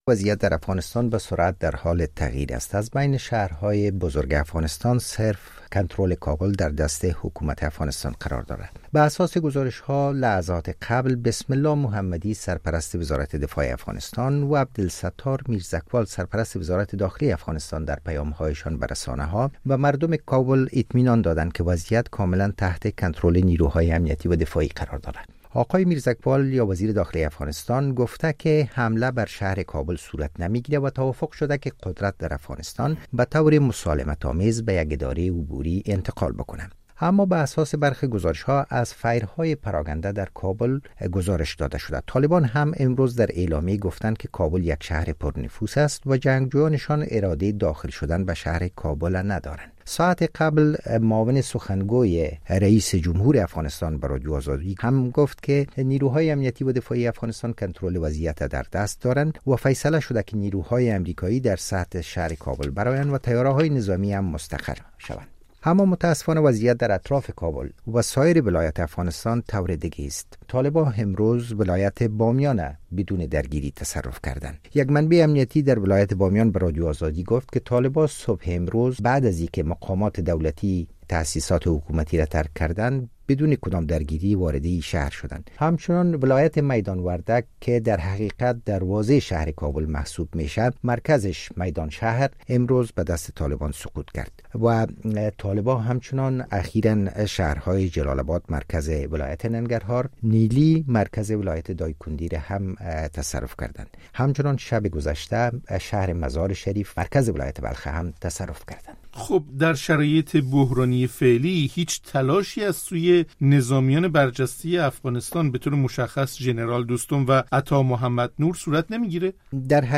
در گفت‌وگویی